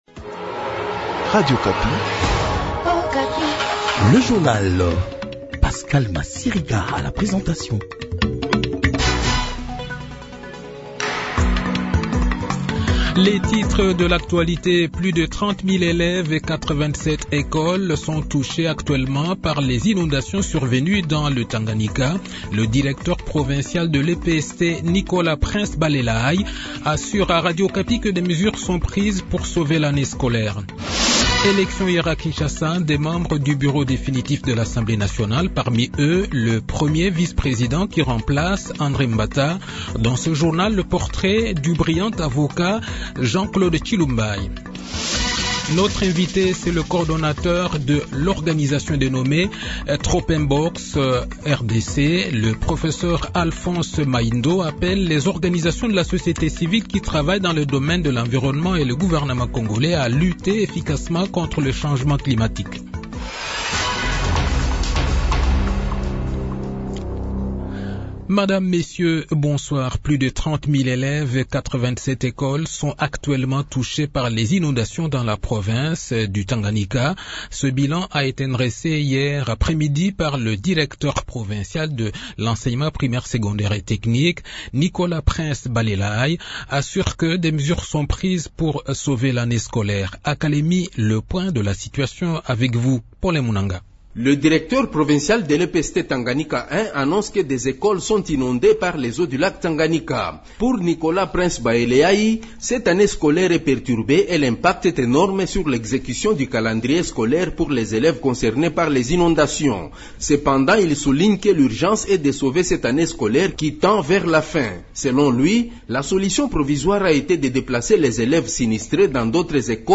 Journal Soir
Le journal de 18 h, 23 mai 2024